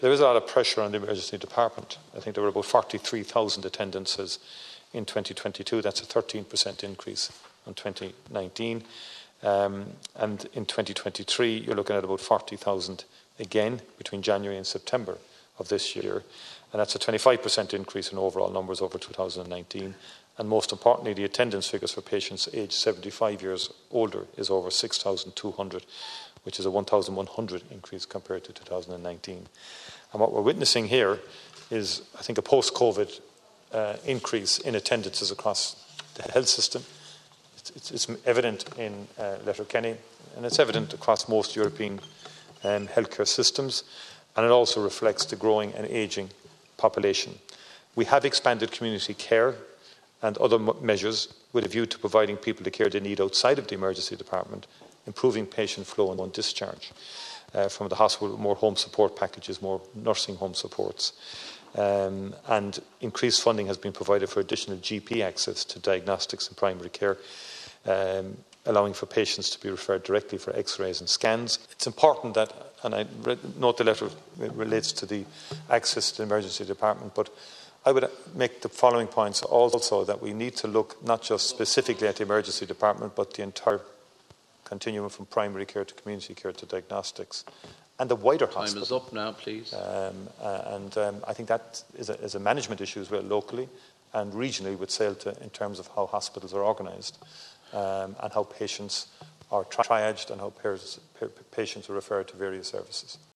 In responding to Donegal Deputy Thomas Pringle, Micheal Martin has acknowledged the pressures at the hospital’s Emergency Department: